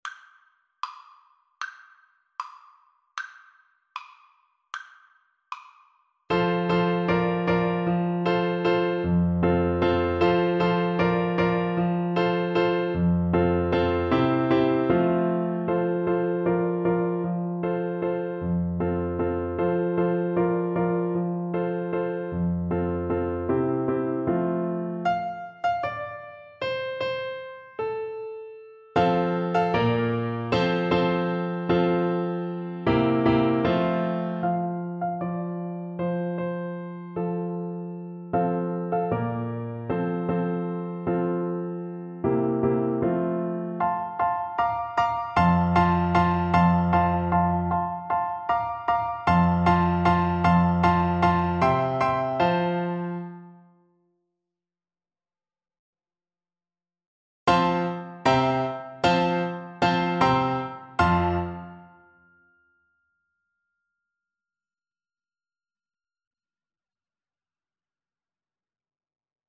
World Africa Zambia Mayo Nafwa (Zambian Trad.)
Clarinet
F major (Sounding Pitch) G major (Clarinet in Bb) (View more F major Music for Clarinet )
4/8 (View more 4/8 Music)
Allegro Energico =240 (View more music marked Allegro)
Traditional (View more Traditional Clarinet Music)
world (View more world Clarinet Music)